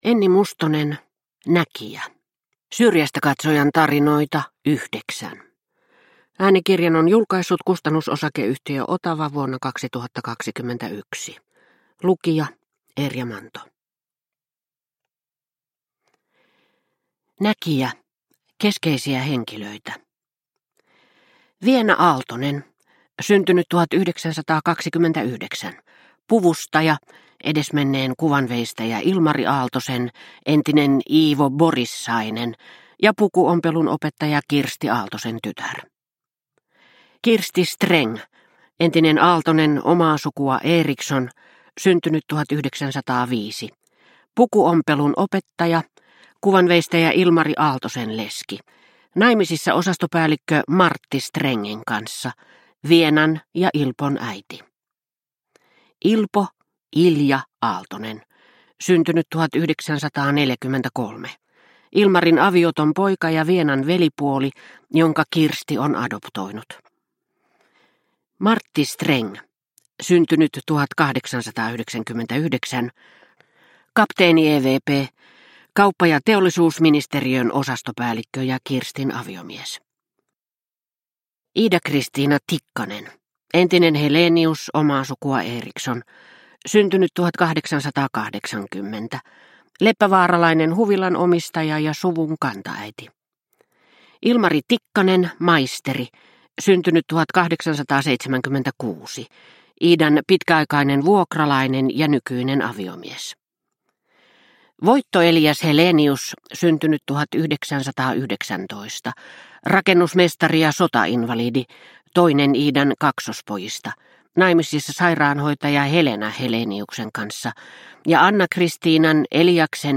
Näkijä – Ljudbok – Laddas ner